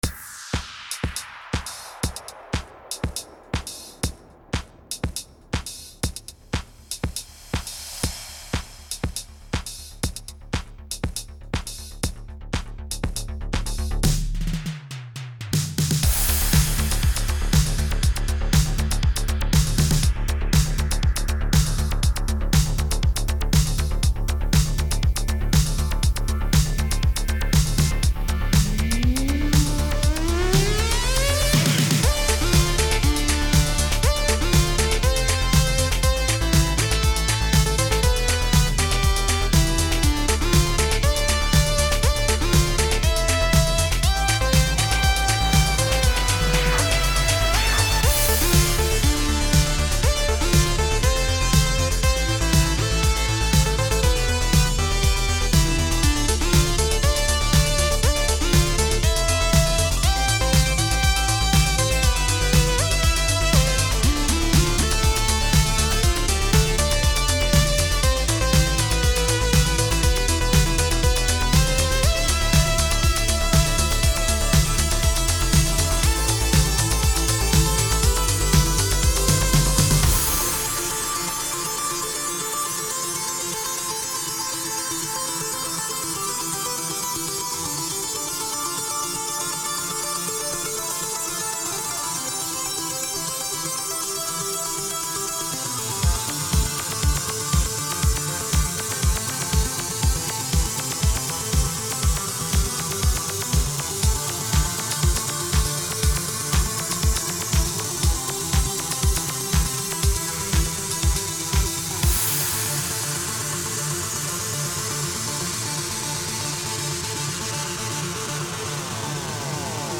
Love that drop!
Love that beat!
They synths and pads however are pure 80's awesome and the melodies themselves are excellent.